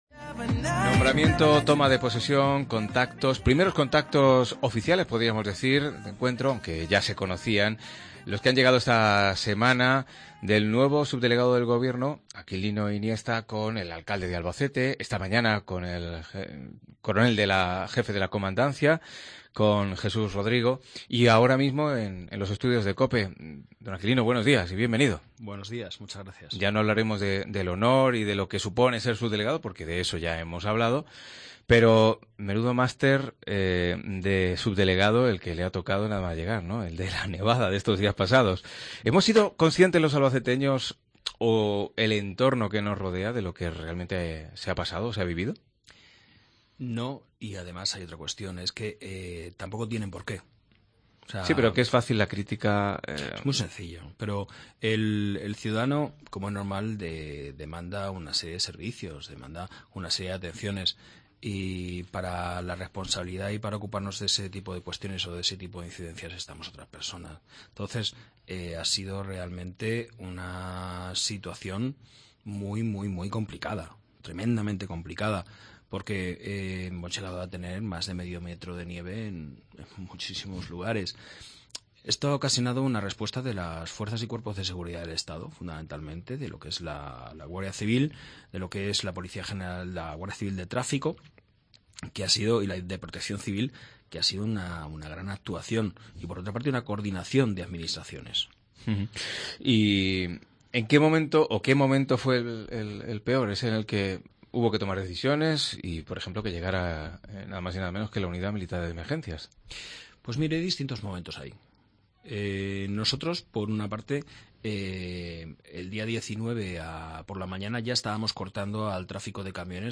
170125 Entrevista subdelegado Gobierno Aquilino Iniesta
Nombrado hace unas semanas por el Gobierno de España, Aquilino Iniesta ha mantenido en esta semana contactos con Javier Cuenca, alcalde de Albacete, así como con el jefe de la Comandancia de la Guardia Civil, Jesús Manuel Rodrigo. Hoy también nos ha visitado a nosotros, a COPE Albacete.